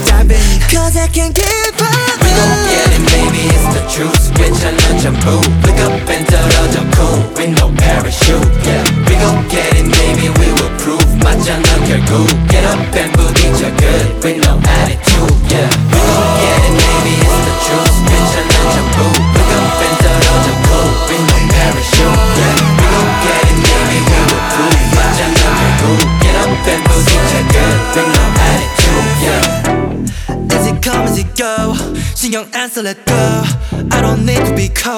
K-Pop Pop
2025-08-11 Жанр: Поп музыка Длительность